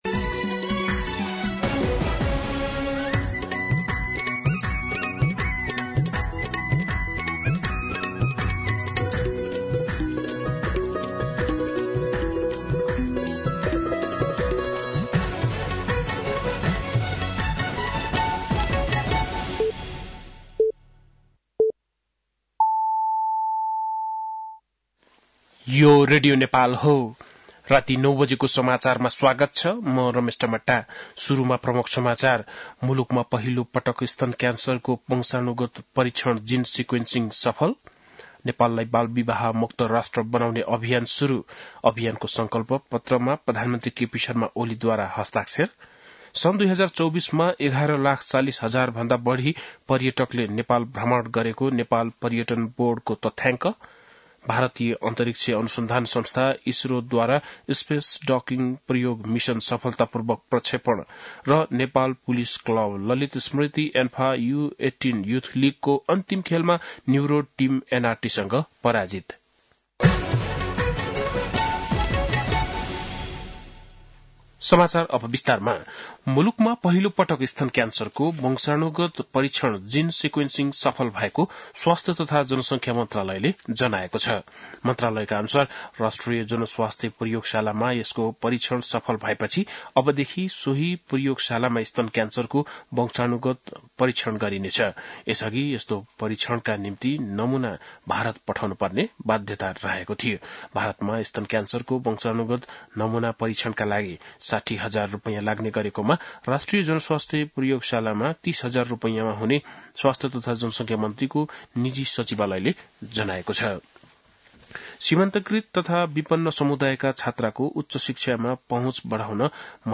बेलुकी ९ बजेको नेपाली समाचार : १७ पुष , २०८१
9-PM-Nepali-News-9-16.mp3